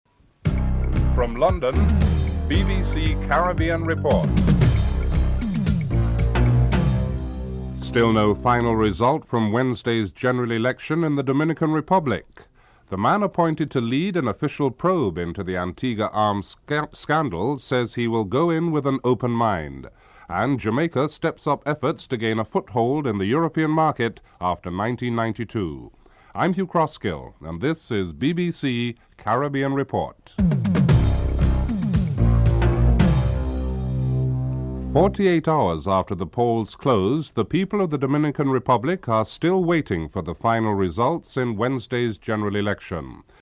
1. Headlines (00:00-00:34)
Interview with Norman Ray, Jamaica's Trade Commissioner in London (08:32-11:31)